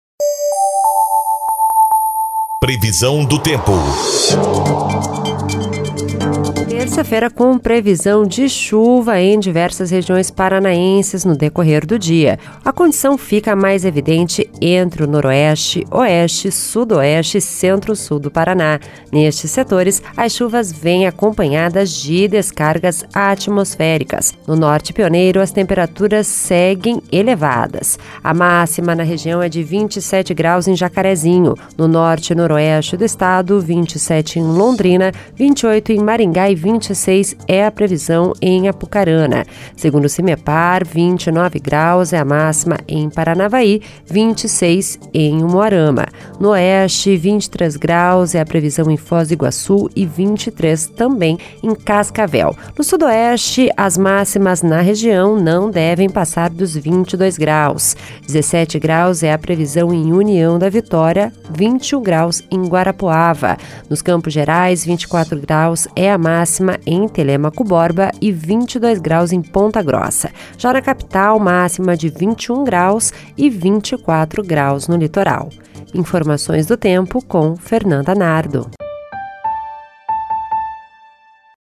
Previsão do Tempo (10/05)